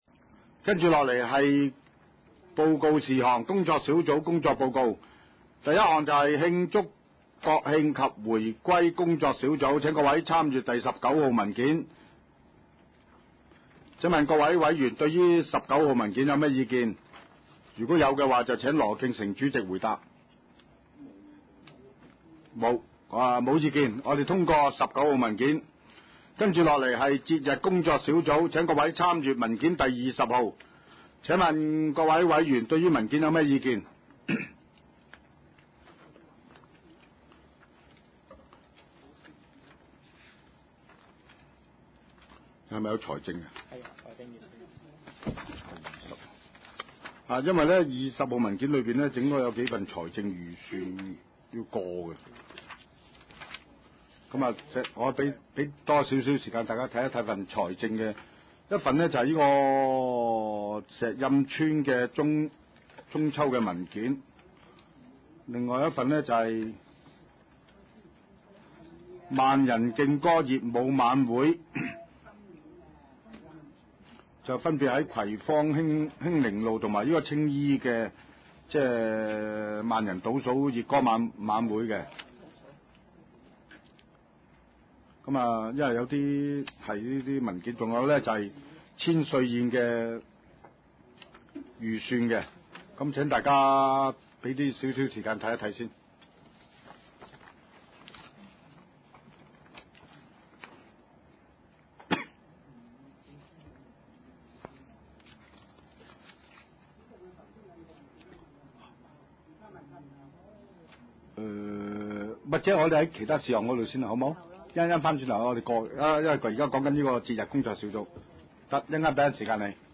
葵青民政事務處會議室